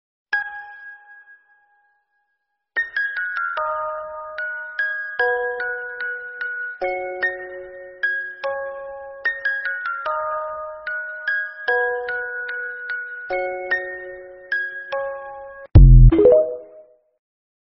Nhạc Chuông Báo Thức